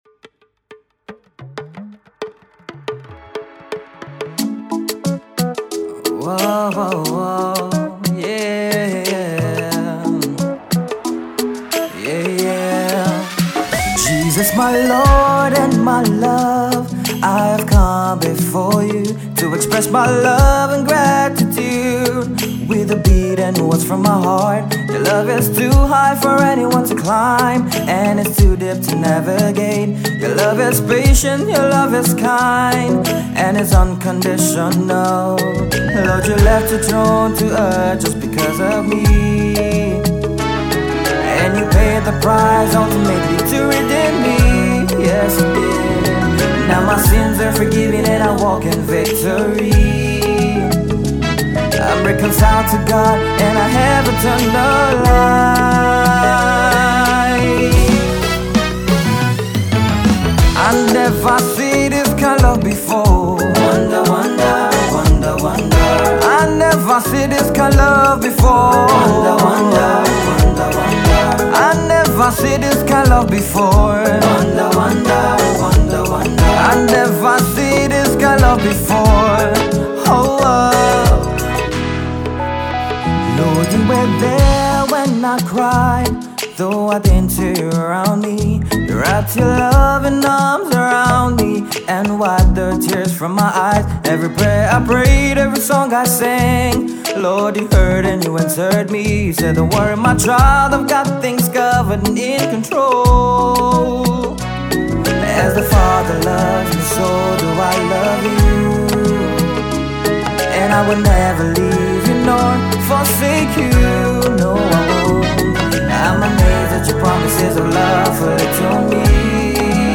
High-life song